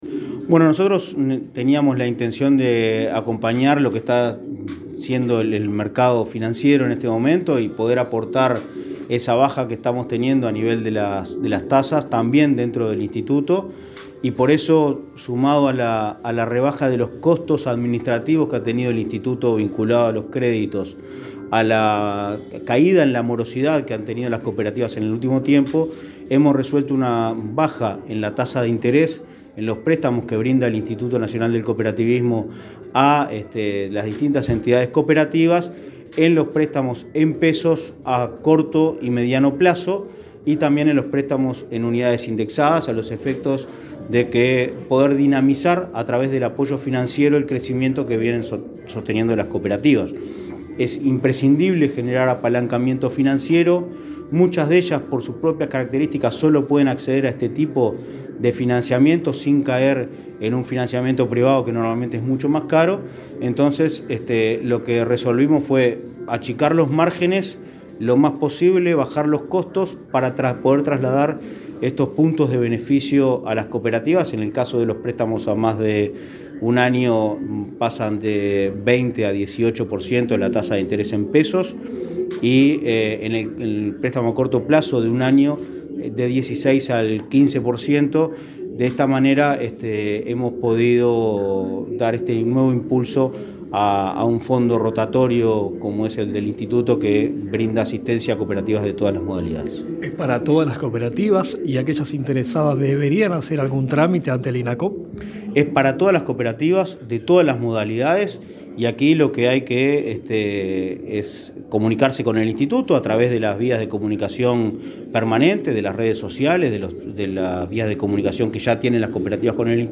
Entrevista al presidente de Inacoop, Martín Fernández